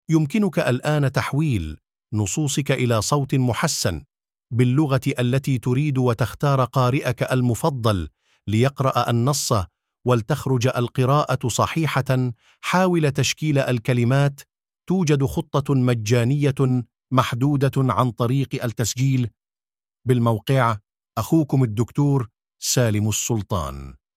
هذا صوت محوّل عن طريق الموقع
ElevenLabs_2023-10-15T14_23_50_Daniel.mp3